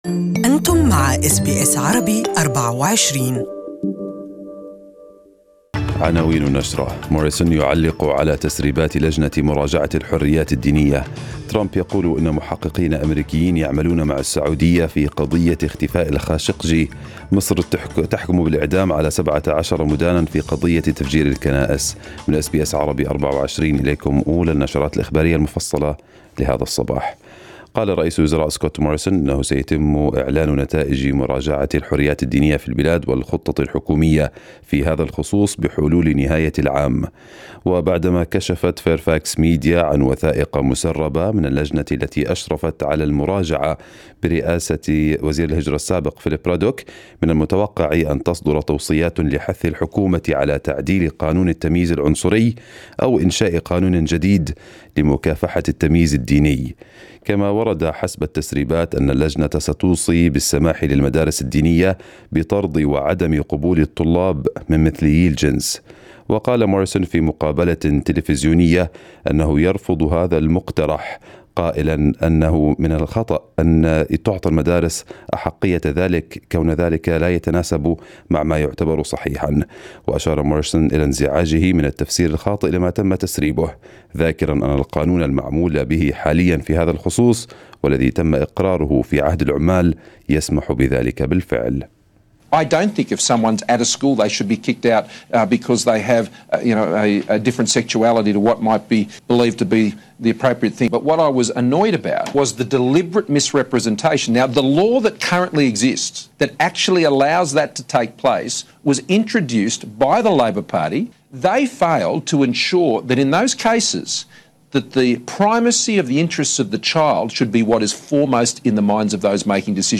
News bulletin of the day